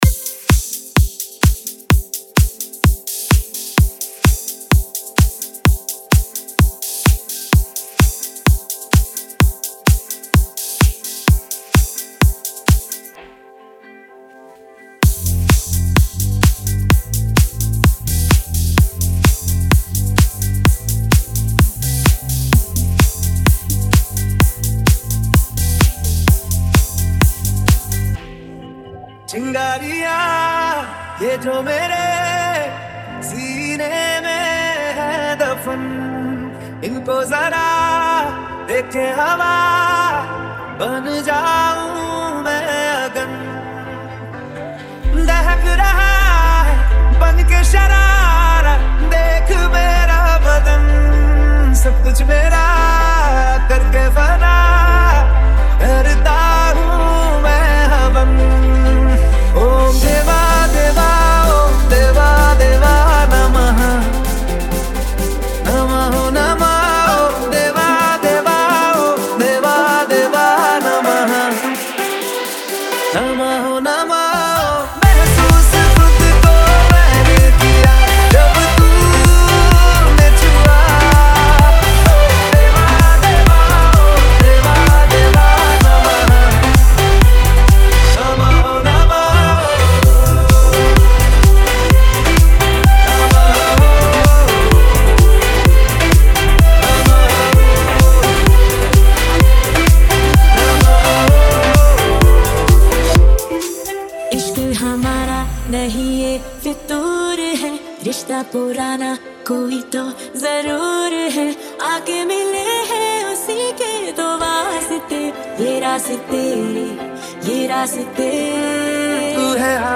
Bollywood DJ Remix Songs